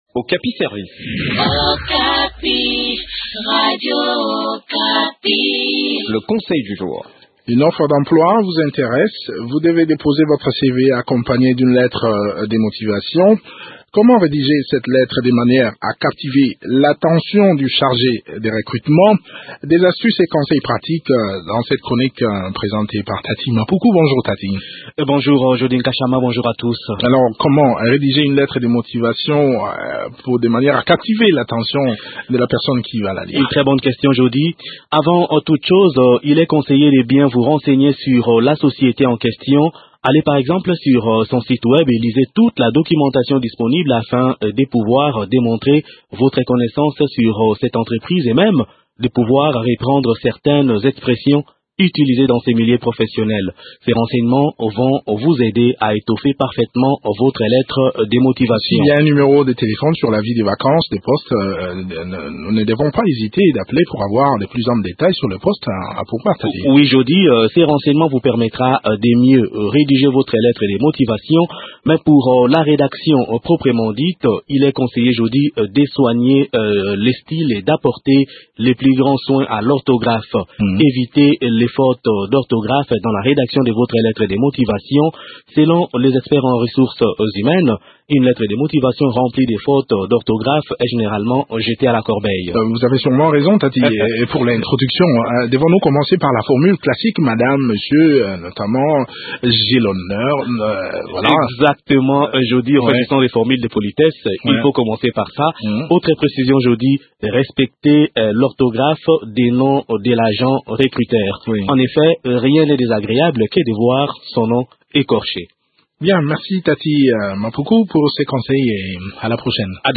Découvrez des astuces dans cette chronique